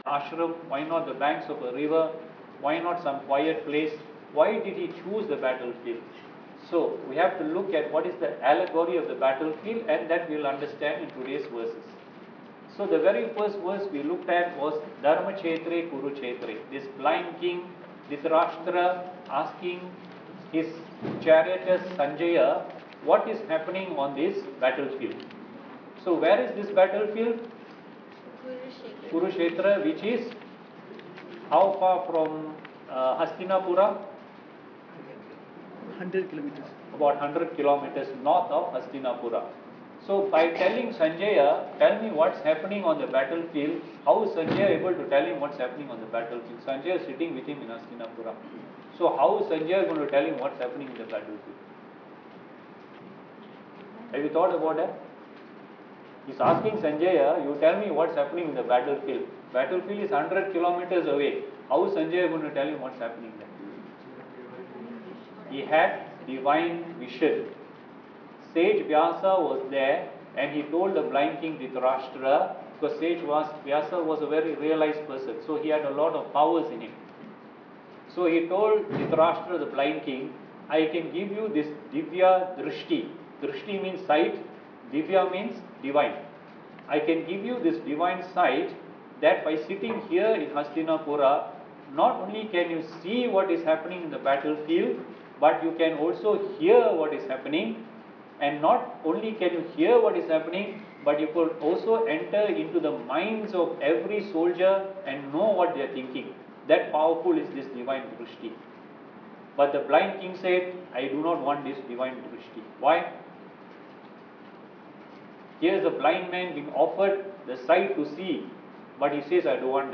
Here is the audio clip from last week’s class!